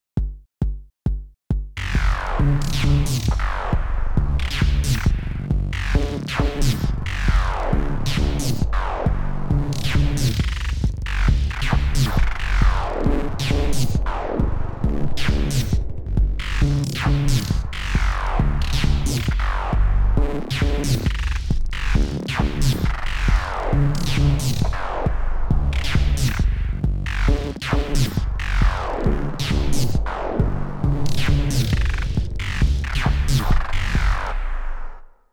I achieved this by triggering the sample many times in quick succession and playing with the speed in which it was played back.
I like the piercing buzzing nature of the sound and so decided to focus on building the track around this.
By default Tidal runs at 135 bpm (0.5625 cps).